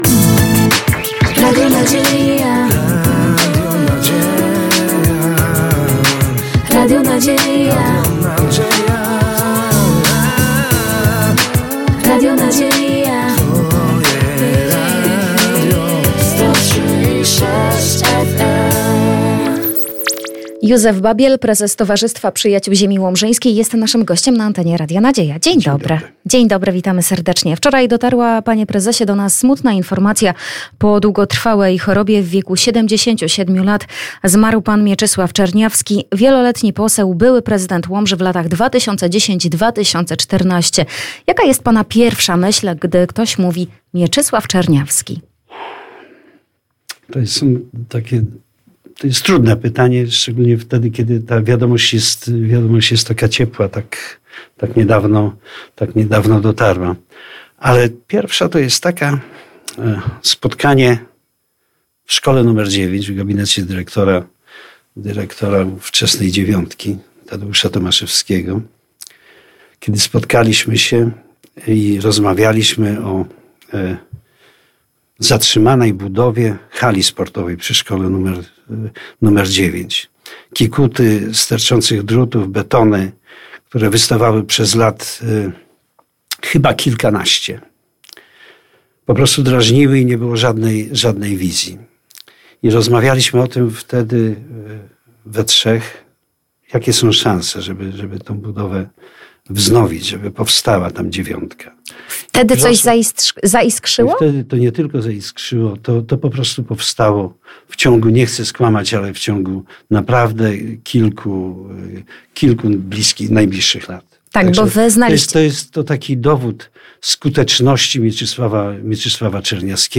Zapraszamy do wysłuchania całej rozmowy.